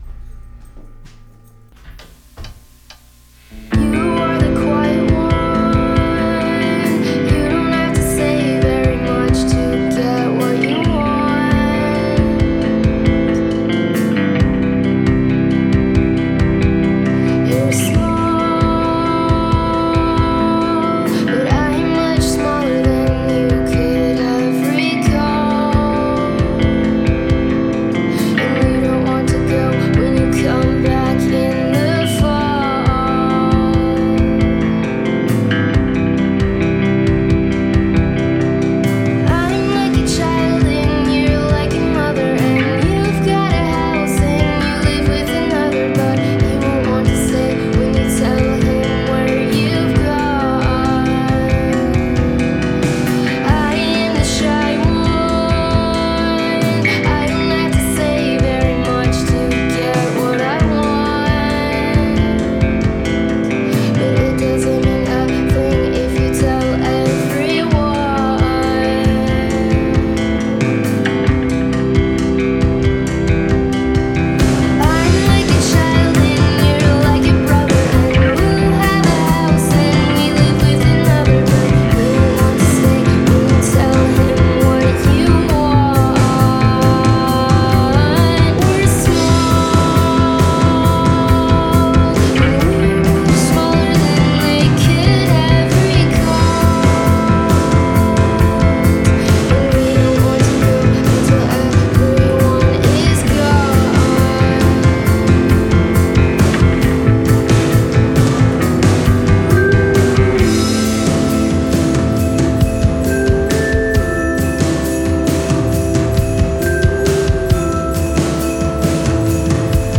vocals, guitar, keyboard
drums, backing vocals
bass